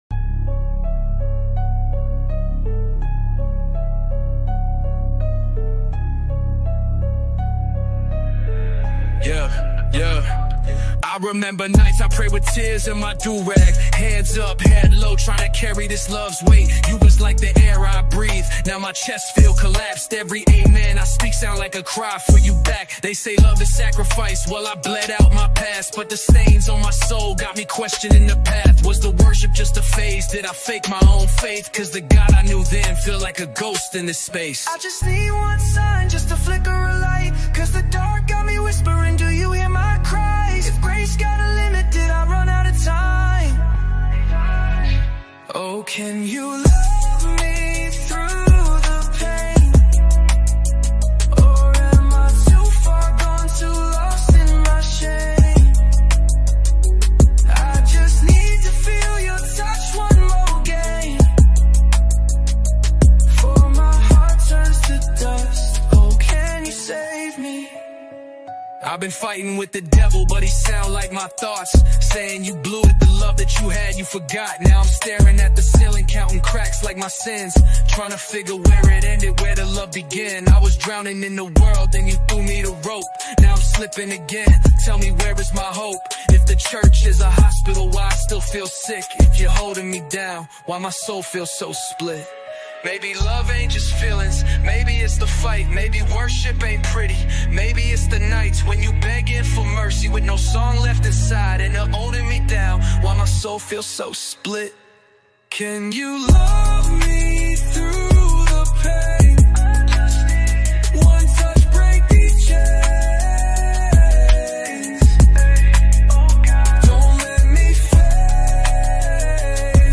Hold Me One Last Time - Gospel Rap